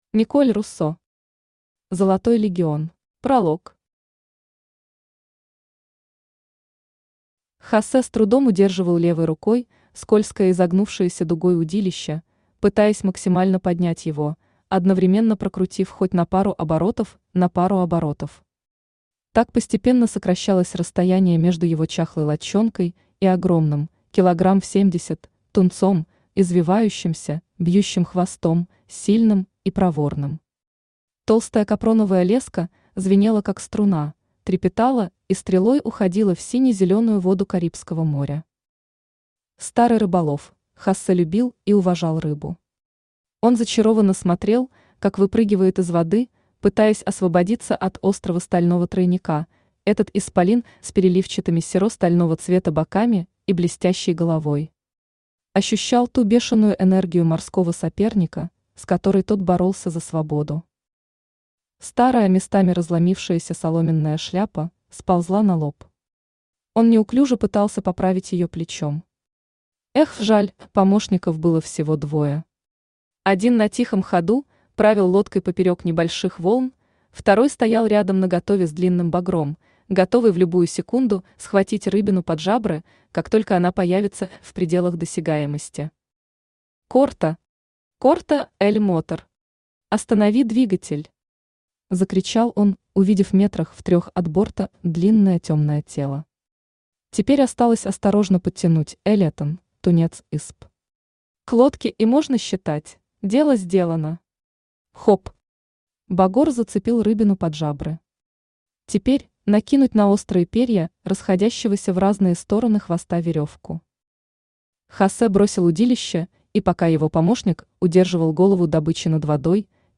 Аудиокнига Золотой Легион | Библиотека аудиокниг
Aудиокнига Золотой Легион Автор Николь Руссо Читает аудиокнигу Авточтец ЛитРес.